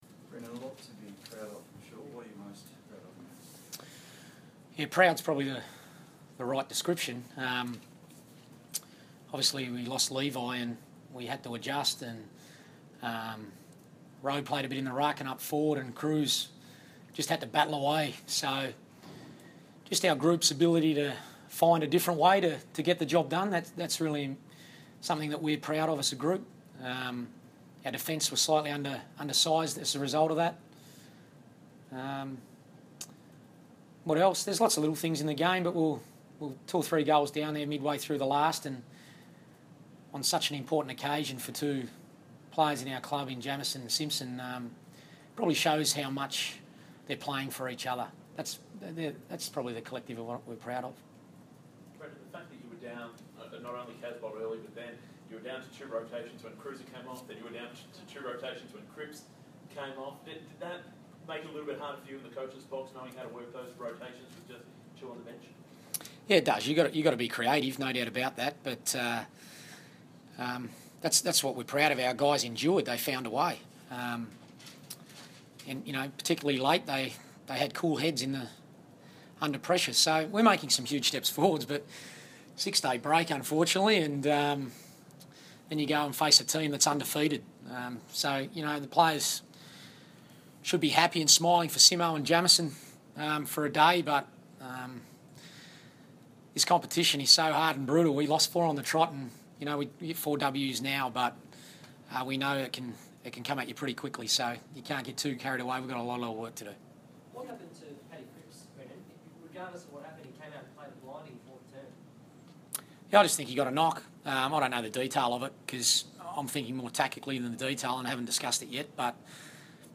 Round 8 post-match press conference
Coach Brendon Bolton speaks to the media after Carlton's Round 8 win over the Power at Etihad Stadium.